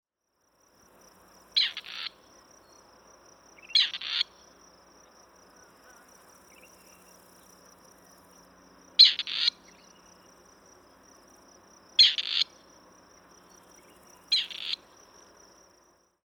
На этой странице собраны звуки, издаваемые куропатками различных видов.
Голос серой куропатки в весеннем лесу